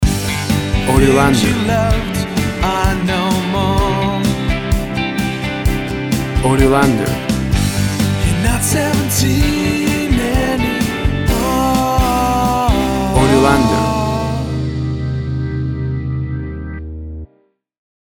Tempo (BPM) 123